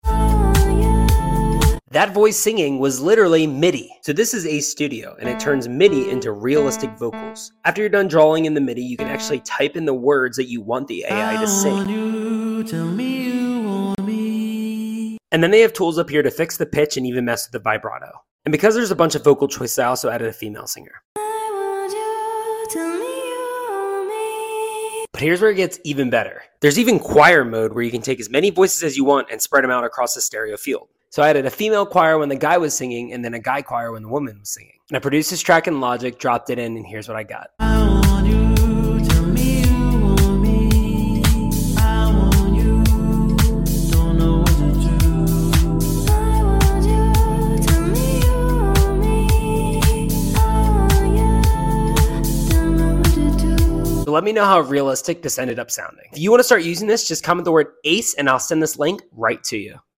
BUT ITS A SINGER and one that sounds very real!